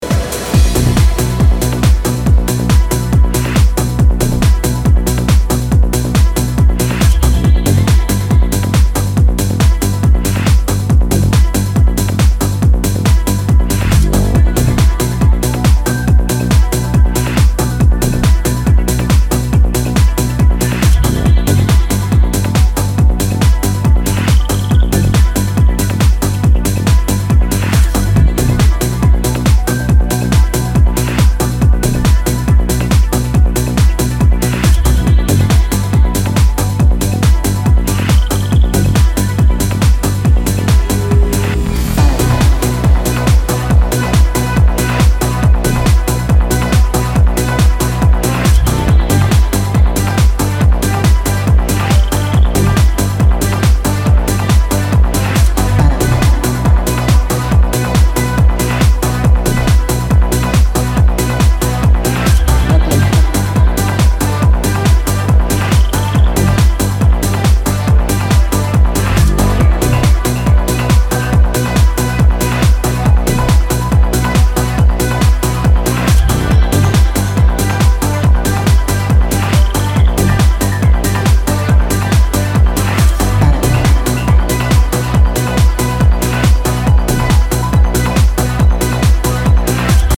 パンピンなランニングベースが最高にファンキーな140BPMフロアフィラー